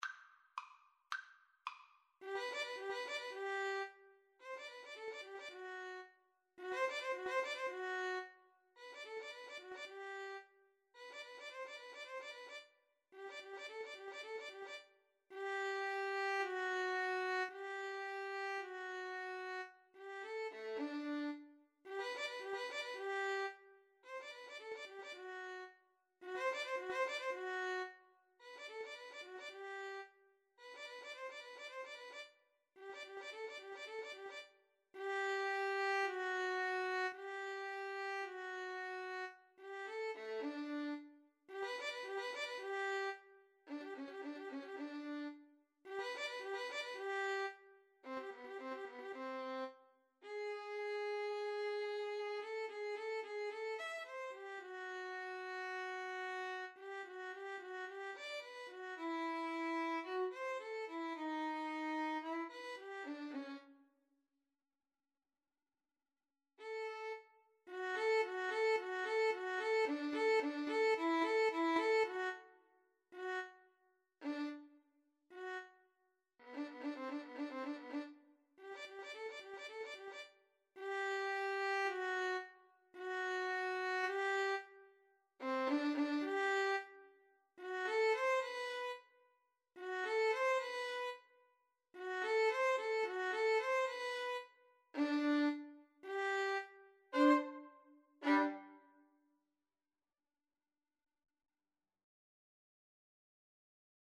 2/4 (View more 2/4 Music)
Allegro assai = 110 (View more music marked Allegro)
Classical (View more Classical Violin Duet Music)